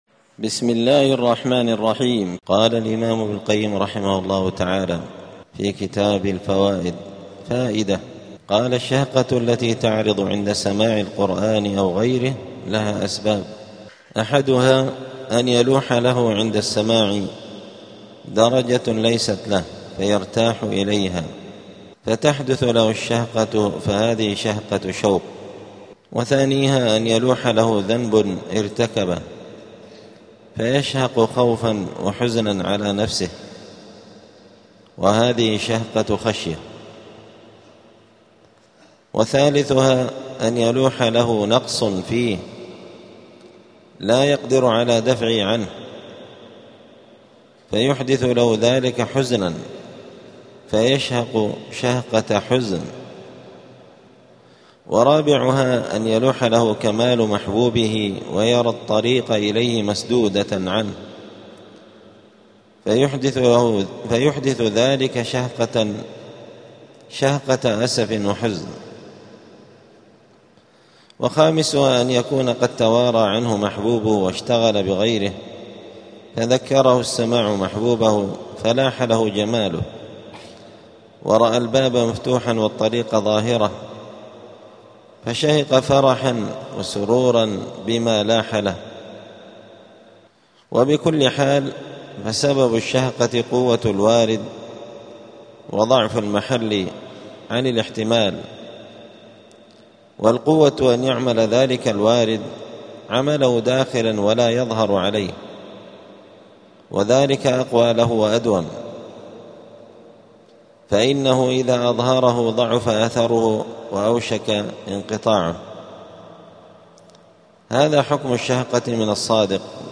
الجمعة 7 جمادى الآخرة 1447 هــــ | الدروس، دروس الآداب، كتاب الفوائد للإمام ابن القيم رحمه الله | شارك بتعليقك | 13 المشاهدات
دار الحديث السلفية بمسجد الفرقان قشن المهرة اليمن